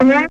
Boing
Boing.wav